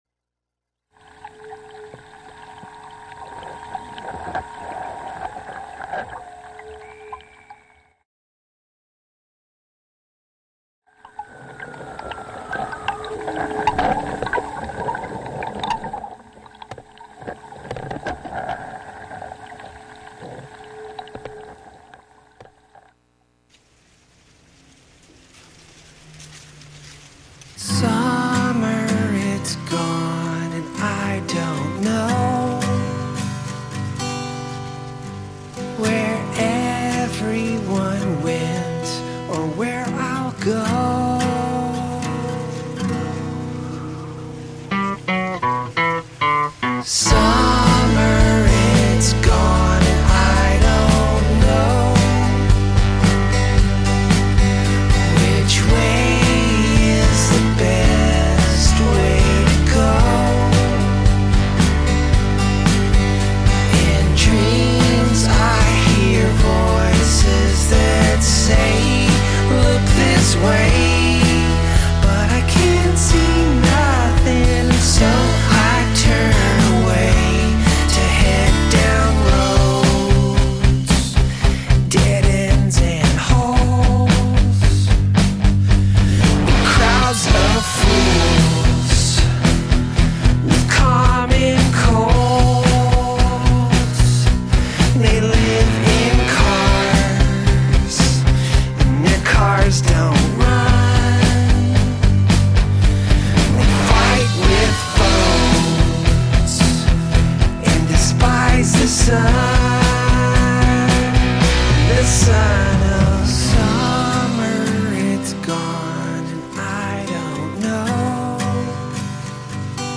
You’re invited to put on your boots and join us every Friday morning as we meander through the wild areas of our modern urban landscape, exploring contemporary and classic Americana, folk, country and elusive material that defies genre.
Broadcast live from the Hudson studio.